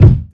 Kick36.wav